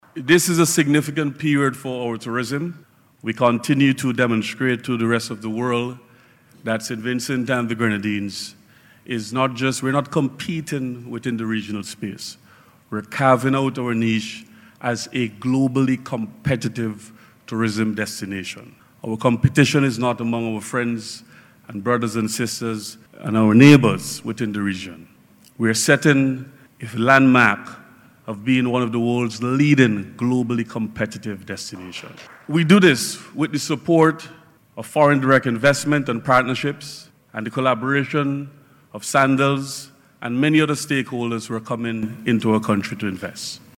Speaking at the recent signing ceremony for the construction of a Beaches Resort at Mt. Wynne/Peter’s Hope, Minister James said this country continues to show the rest of the world that it is carving out a niche as a globally competitive destination.